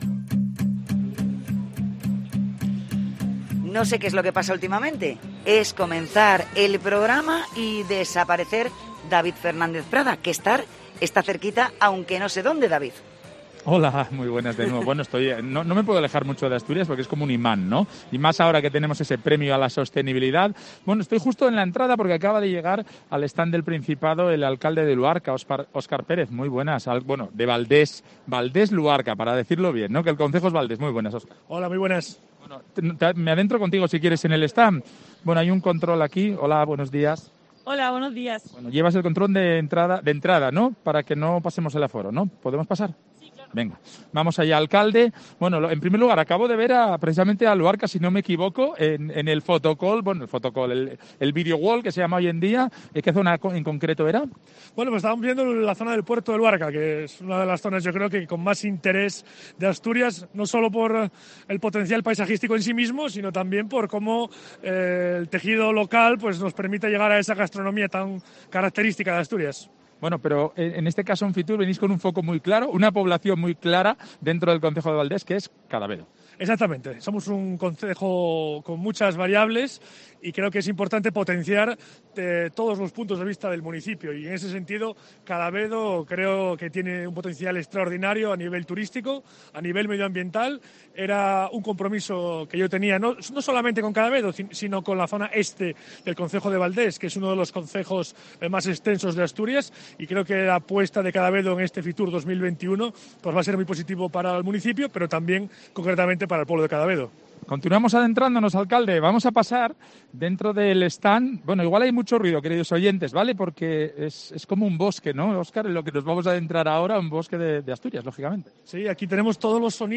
Entrevista al alcalde de Valdés, Óscar Pérez